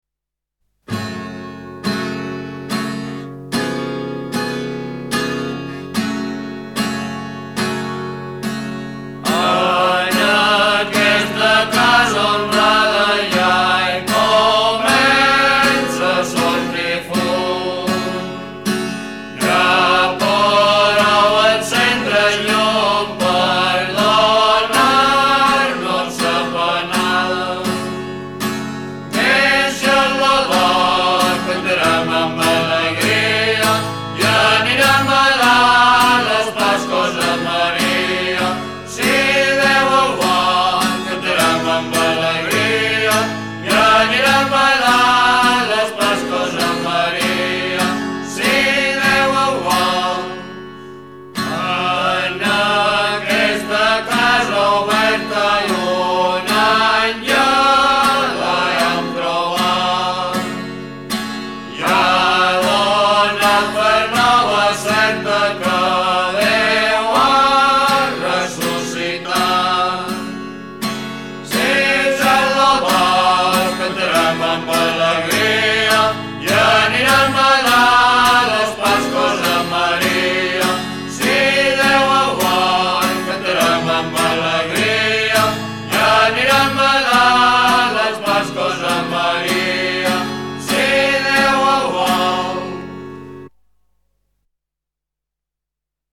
Ecco un esempio di canzoni popolari al riguardo:
La tradizione di cantare a Pasqua è in realtà comune in tutti i Paesi Catalani; non è strano, se pensiamo che una volta la Quaresima era un periodo di astinenza rigorosa, senza alcuna allegria o celebrazione. È il caso del Deixem lo dol, cantato a Mallorca e Minorca in diverse versioni, e delle caramelles della Catalogna ed Eivissa. Vi lascio una versione del Deixem lo dol raccolta da Francisco García Matos negli anni 1940 per la sua opera "Magna Antología del Folklore Musical de España":